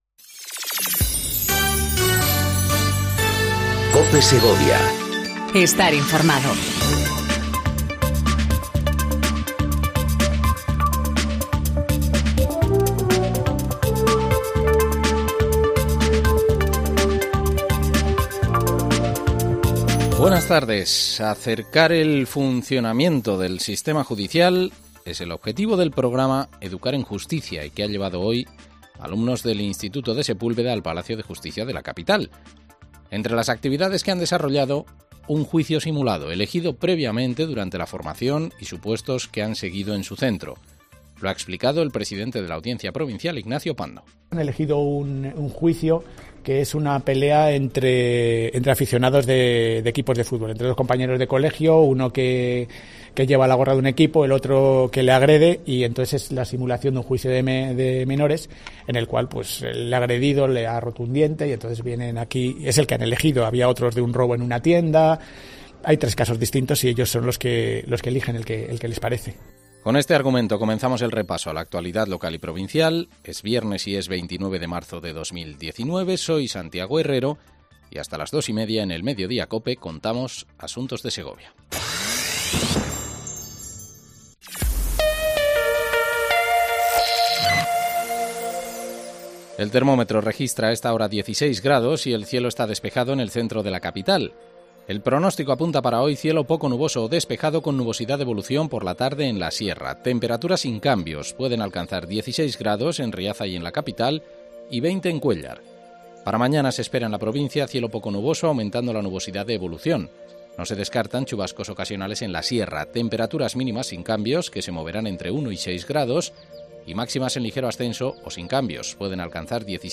INFORMATIVO DEL MEDIODÍA EN COPE SEGOVIA 14:20 DEL 29/03/19